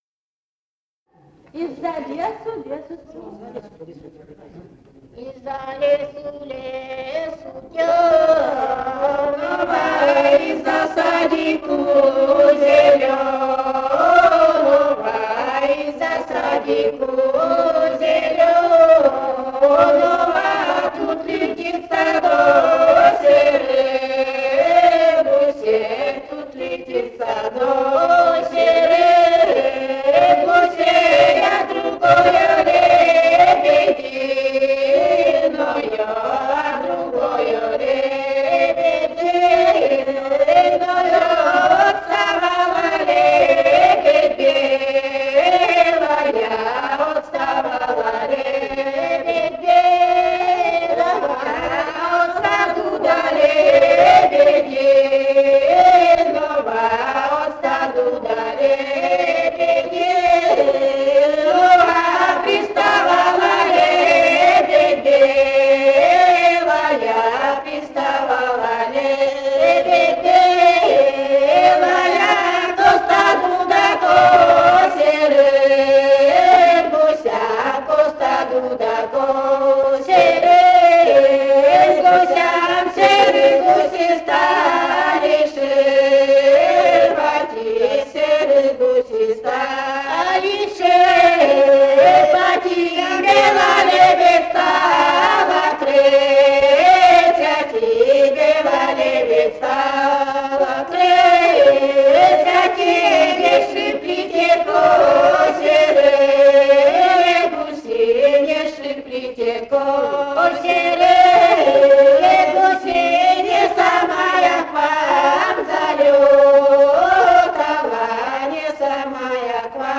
«Из-за лесу, лесу тёмного» (свадебная).